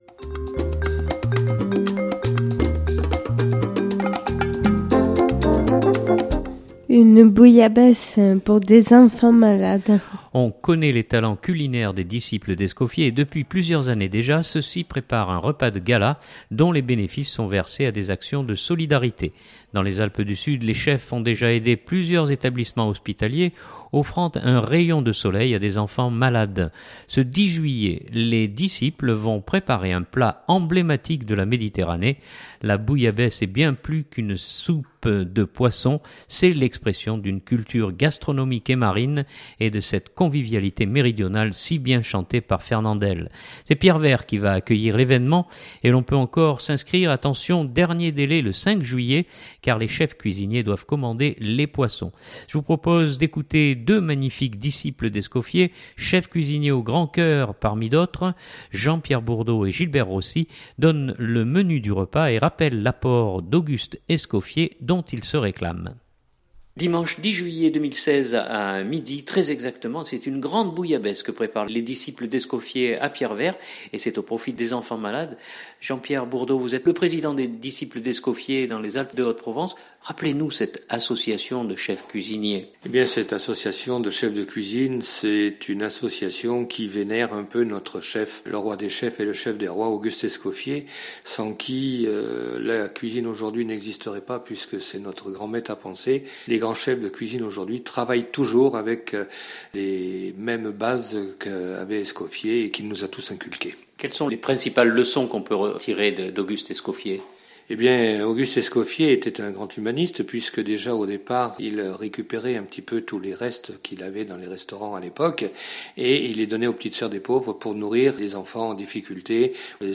Je vous propose d’écouter deux magnifiques Disciples d’Escoffier, chefs cuisiniers au grand coeur parmi d’autres :